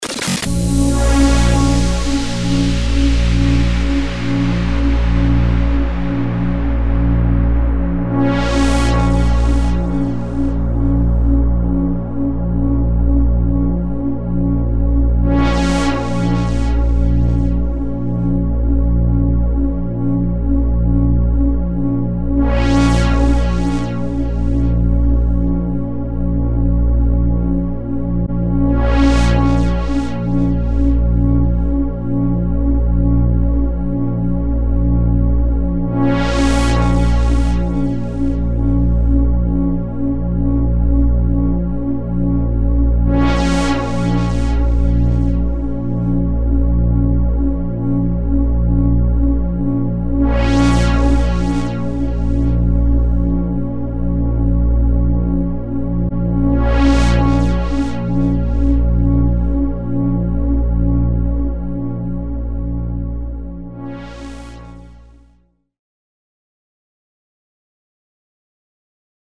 DRONE 075 – SURGING FORWARD – 138BPM – A#MIN